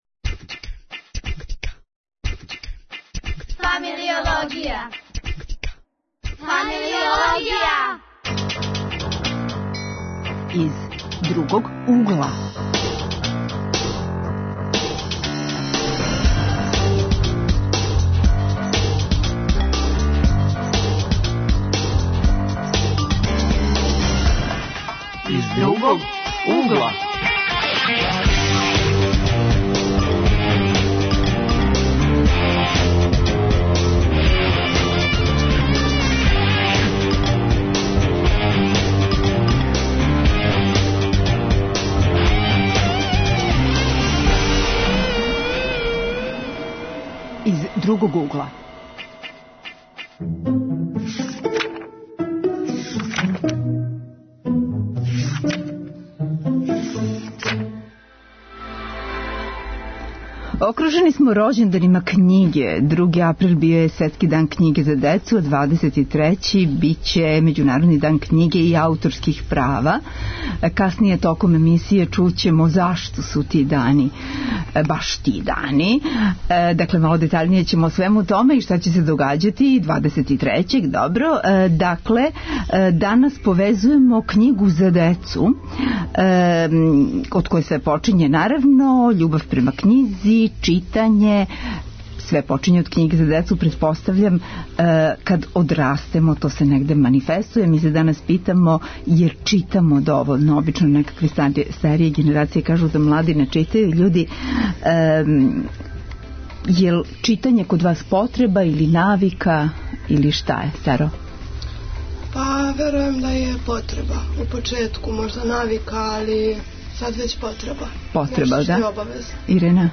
Гости - средњошколци из Београда, а телефоном укључујемо младе из Новог Бечеја, Бора и Нове вароши.